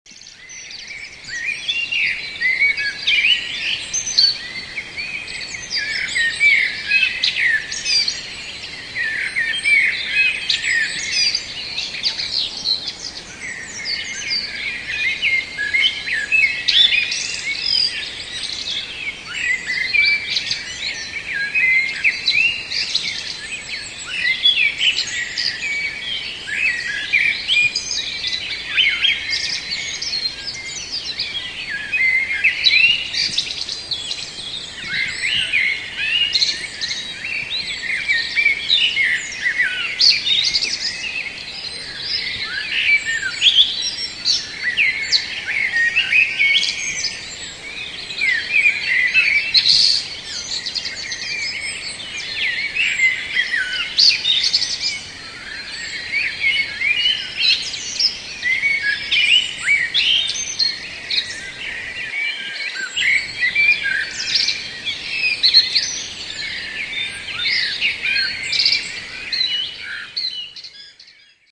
chóru kosów w kwietniowym lesie na 45 minut przed wschodem słońca.